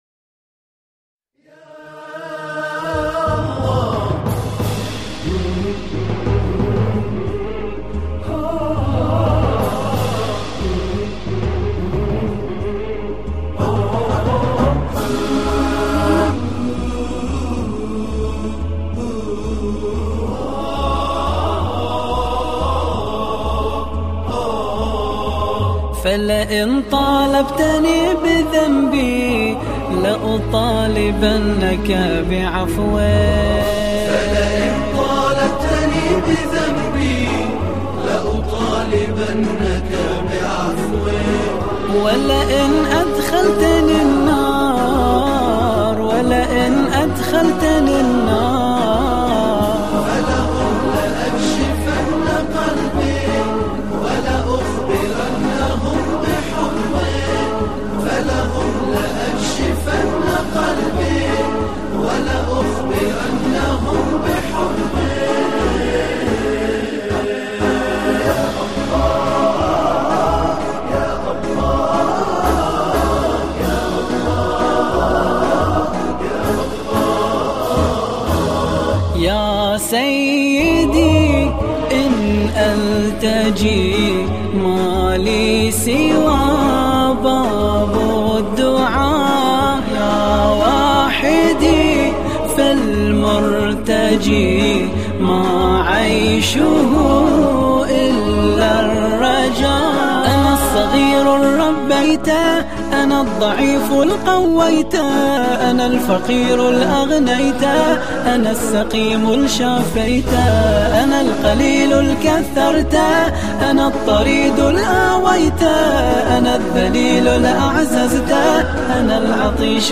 با نواهای دلنشین